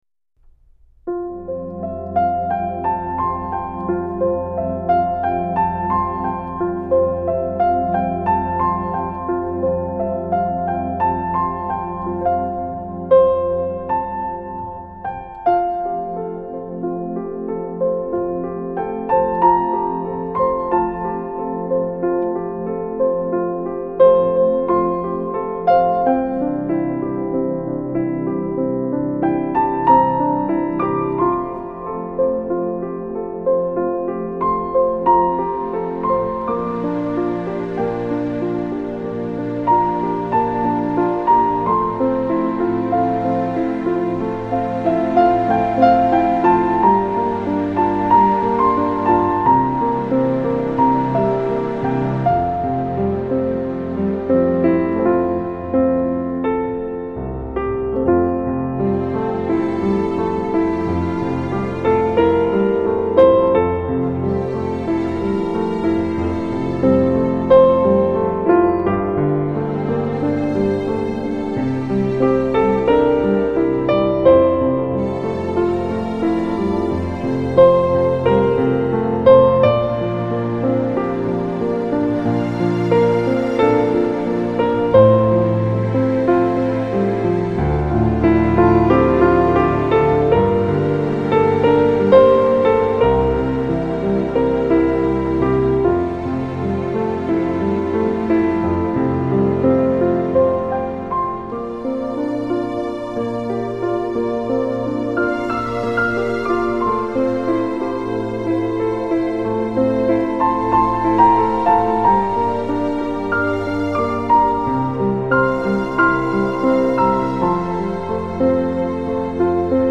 透过钢琴的絮语把幸福传递出去。